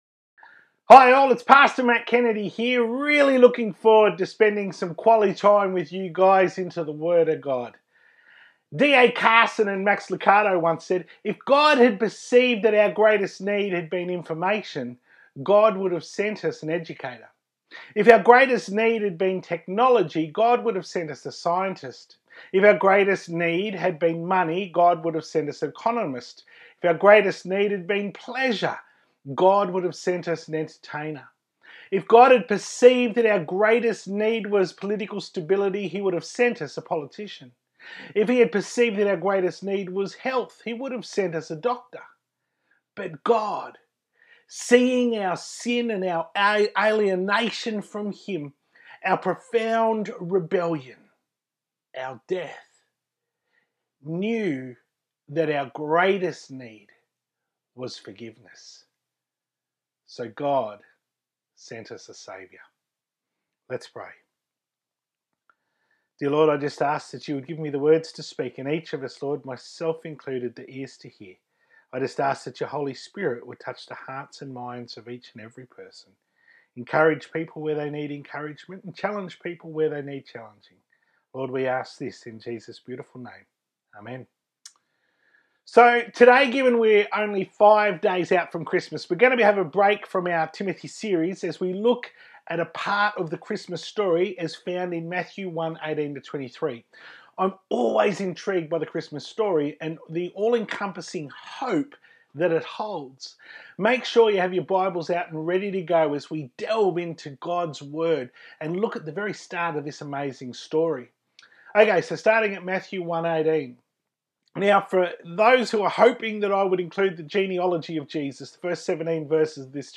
To view the Full Service from 20th December 2020 on YouTube, click here.